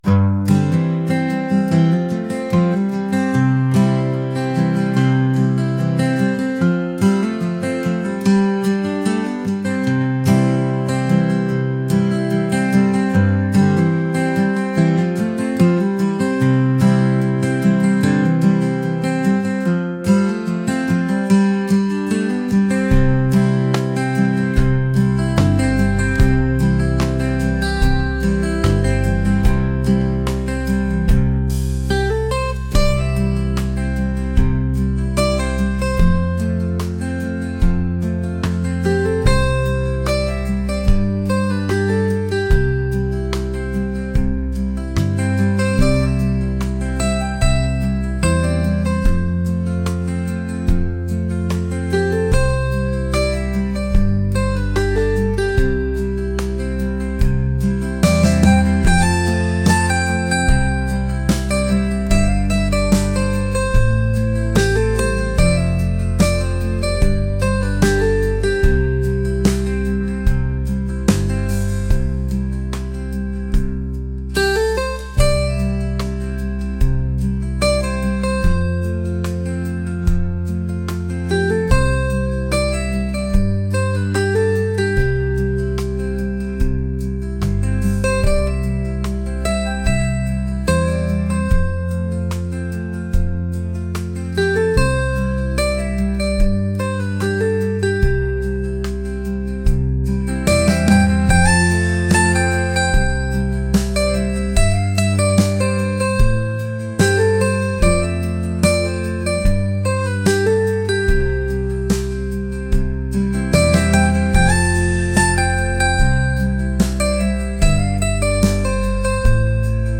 mellow | folk | acoustic